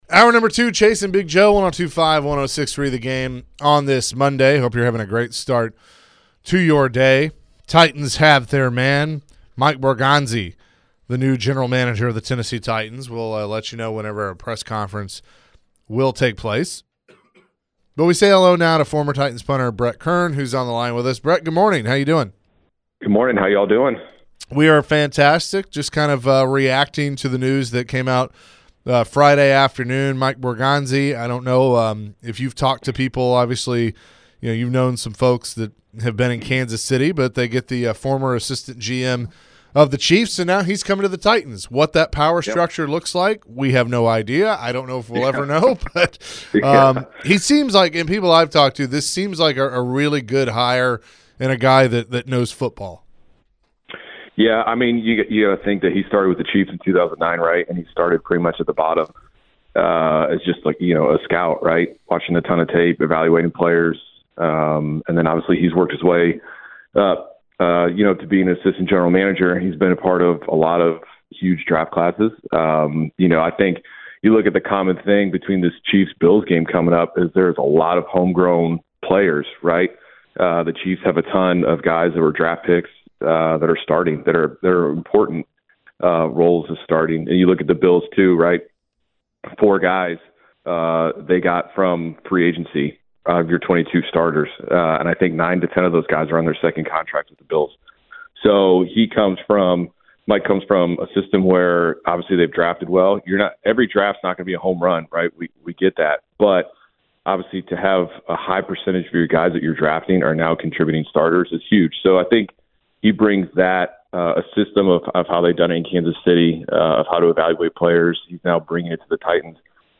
Former Titans Punter Brett Kern joined the show and shared his thoughts on the Titans recent hire of General Manager Mike Borgonzi.